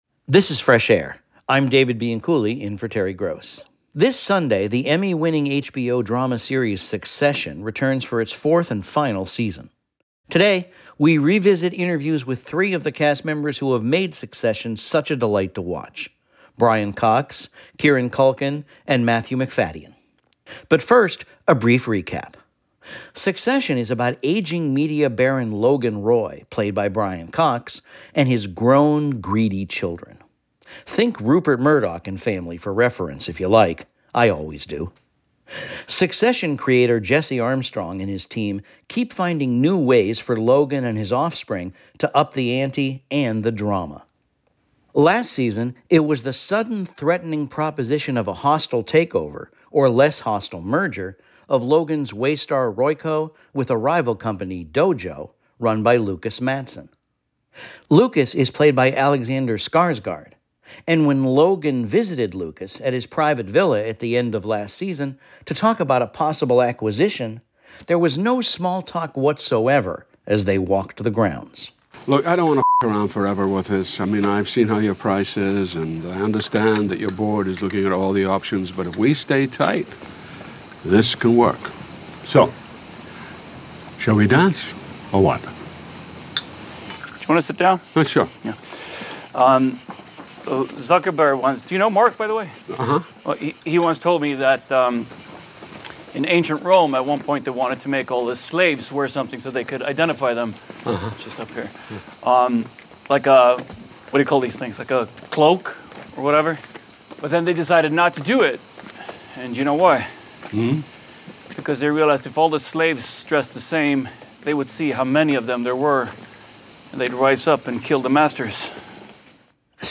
We listen back to our interviews with Brian Cox, who plays Logan Roy the patriarch in a family-owned business empire, Kieran Culkin, who plays Roman, the immature and jokey son, and Matthew Macfadyen, who plays the put-upon son-in-law Tom Wambsgans.